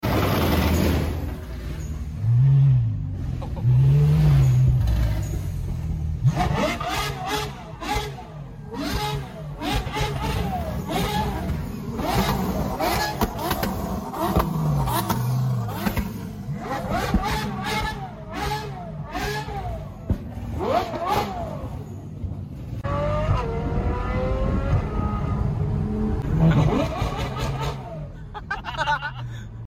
Revving The Ferrari F12 At Sound Effects Free Download